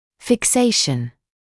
[fɪk’seɪʃn][фик’сэйшн]фиксация, закрепление